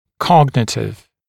[‘kɔgnətɪv][‘когнэтив]когнитивный, познавательный